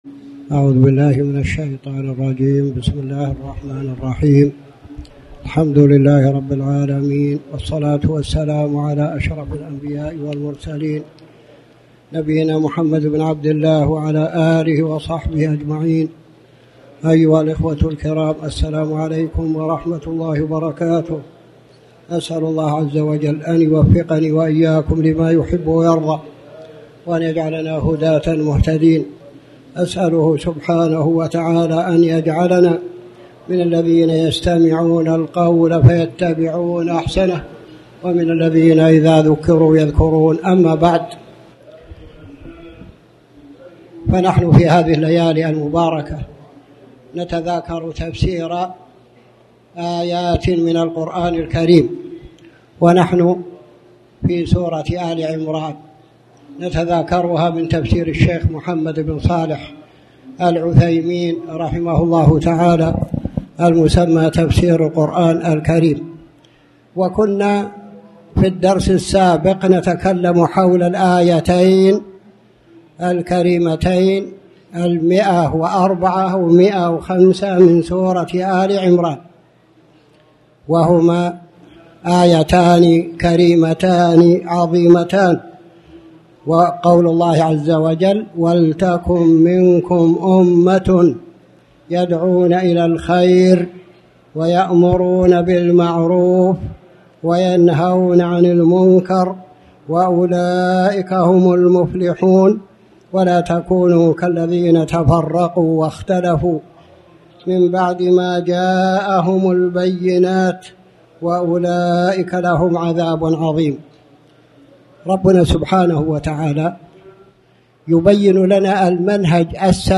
تاريخ النشر ٢١ ربيع الأول ١٤٣٩ هـ المكان: المسجد الحرام الشيخ